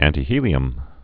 (ăntē-hēlē-əm, ăntī-)